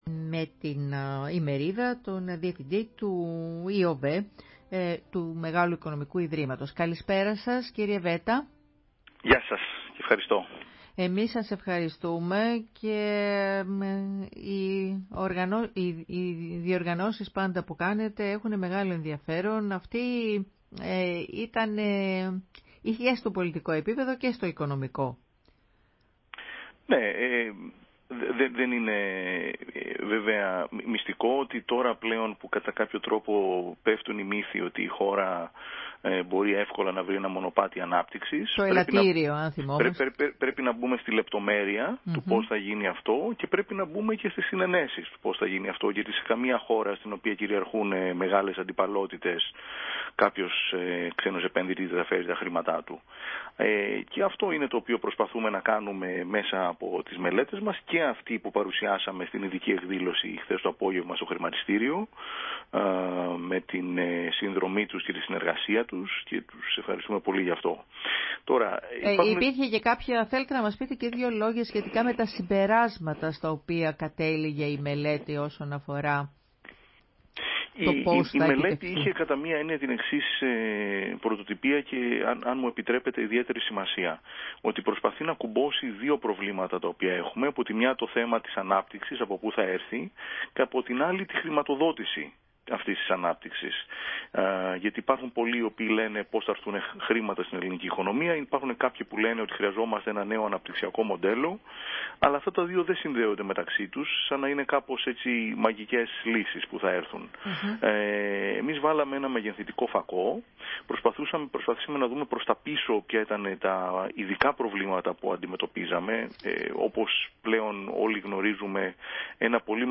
Συνέντευξη
στη ραδιοφωνική εκπομπή «Η φωνή της Ελλάδας»